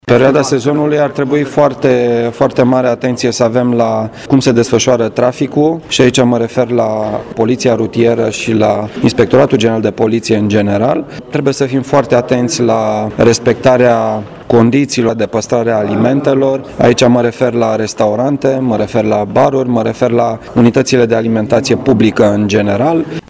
Prefectul Marian Rasaliu ne-a spus care sunt priorităţile, pentru ca sezonul estival 2018 să se desfăşoare fără probleme: